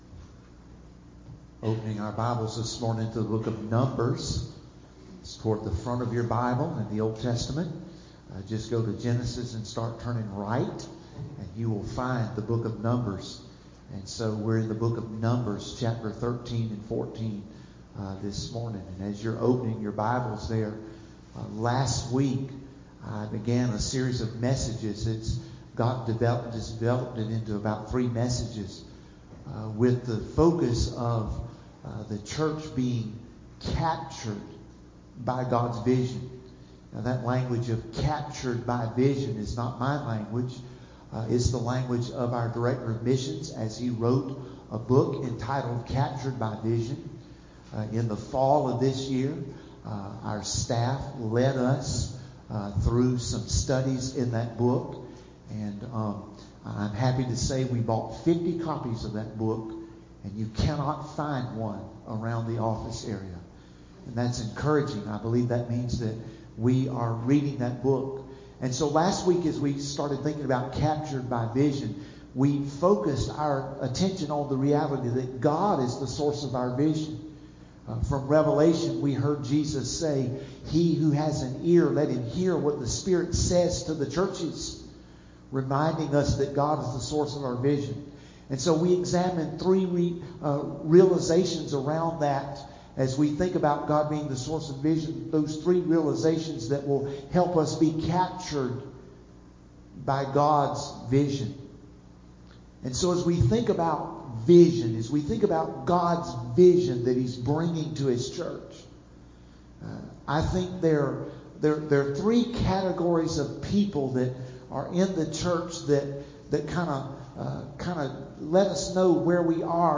2-23-20-Website-sermon-CD.mp3